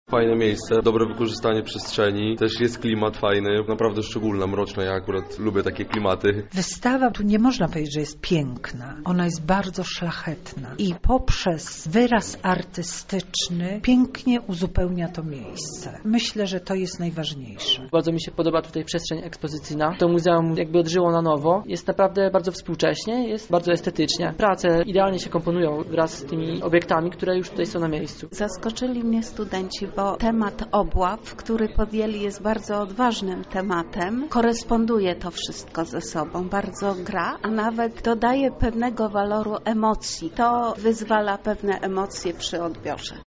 Nasz reporter zapytał uczestników o wrażenia podczas wernisażu.